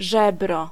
Ääntäminen
IPA: [kot]